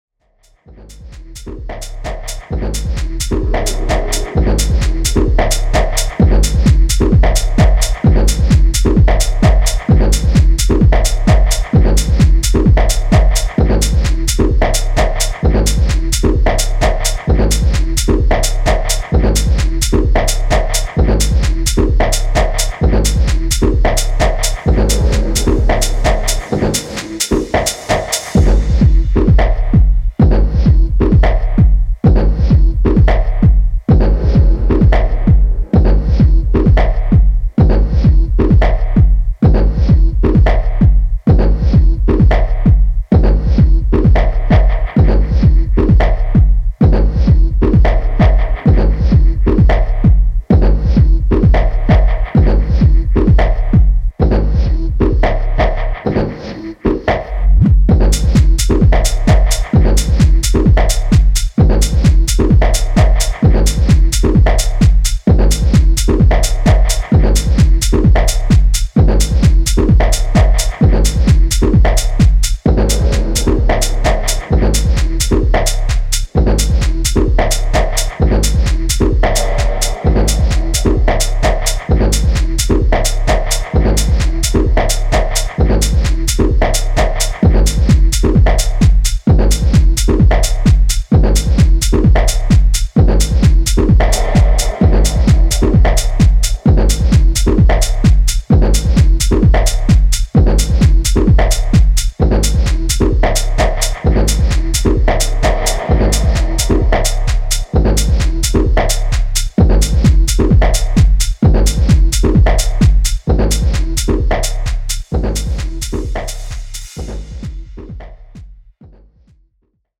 Electro House Techno